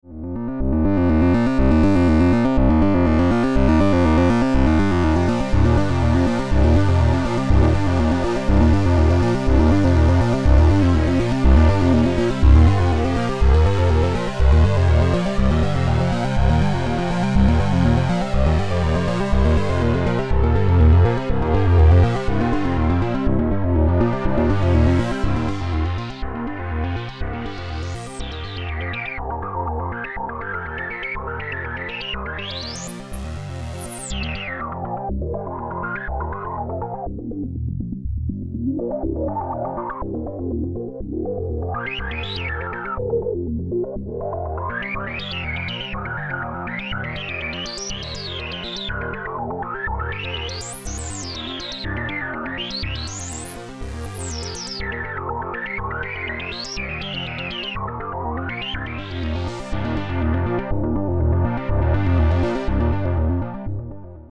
3PSeqDemo6.mp3